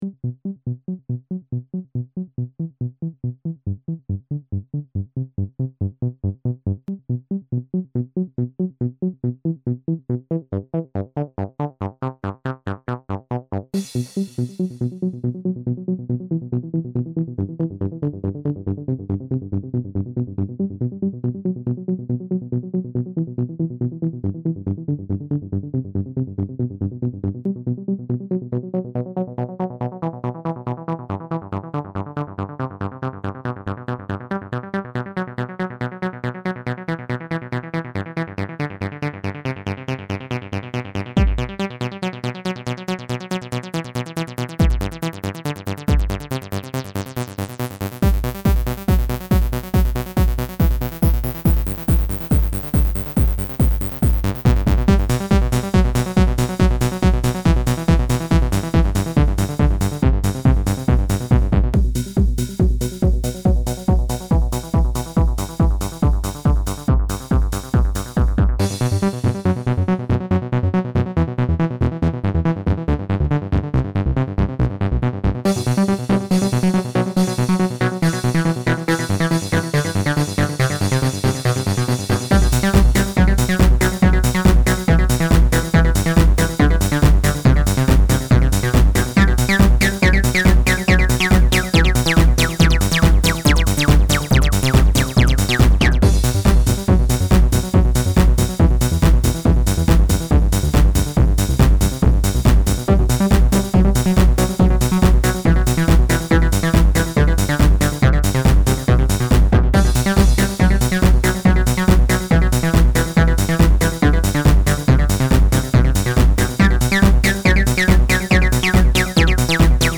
• Quality: 22kHz, Mono